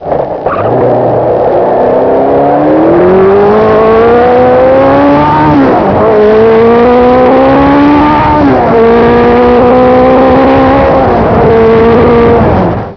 My current bike is a vintage 1974 Kawasaki 900 Z1, the grand daddy of today's super bikes. Like most other Z1's on the road, mine has been extensively modified; Kerker 4-1 exhaust header, Mikuni 29mm slide valve carburetors, K&N air filter, DYNA III electronic ignition, Koni adjustable rear shocks, and a high speed steering damper.
Z1 going through the gears.